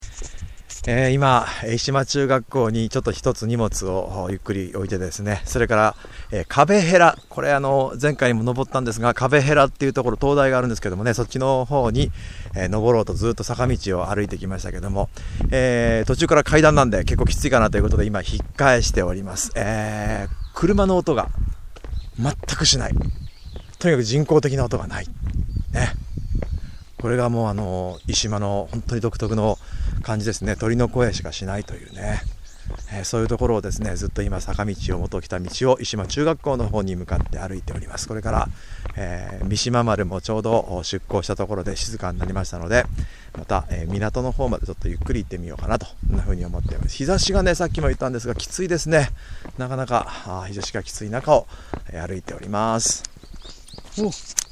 どんなに感覚を研ぎ澄ましても、微かな車の音も聞こえず、排気ガスの臭いも全く感じない空間!!
ただ自分達の足音と鳥の声しか聞こえない!!